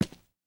immersive-sounds / sound / footsteps / rails / rails-05.ogg
rails-05.ogg